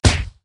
punch_impact_02.ogg